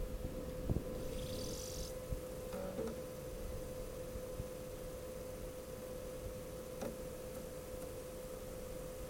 描述：一个ps2开始游戏